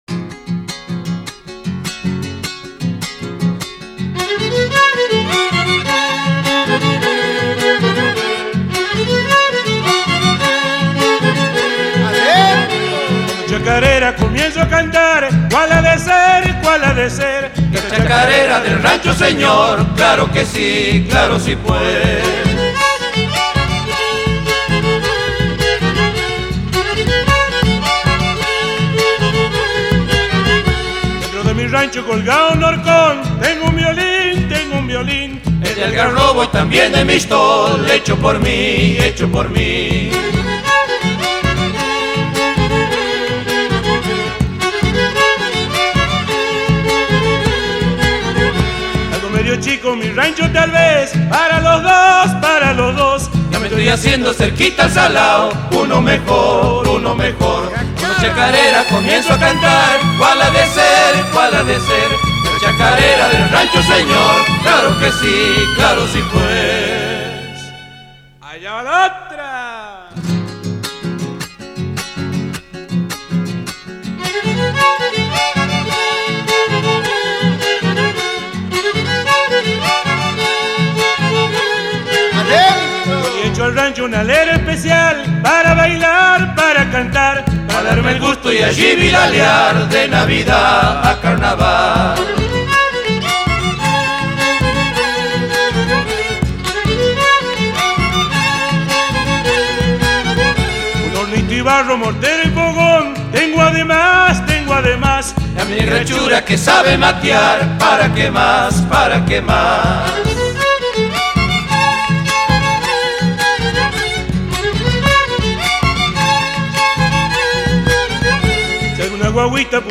Чакарера
Очень позитивный танец!!!
Типичные инструменты, на которых играется чакарера - гитара, бомбо (разновидность перкуссии) и скрипка, хотя в настоящее время вариантов намного больше.